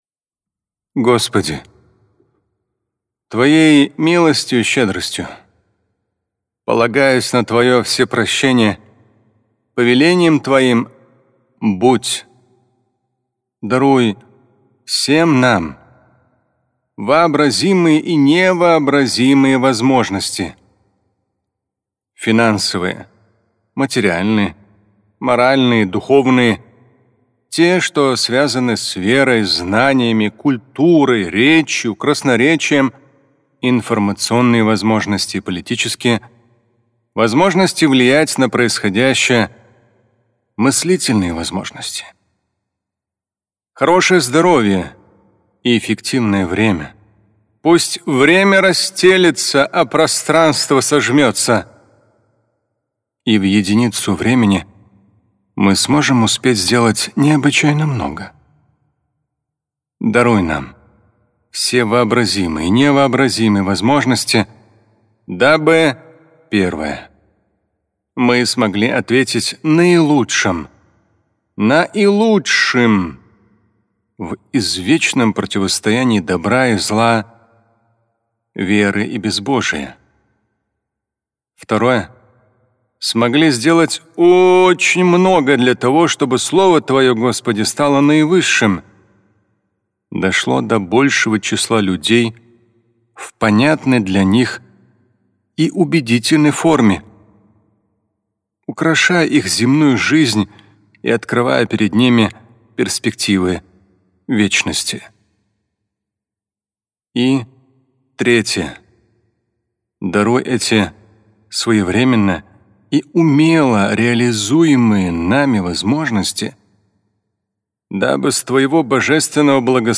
Молитва (дуа) Шамиля Аляутдинова
Дуа имама Шамиля Аляутдинова. Дуа Триллионера.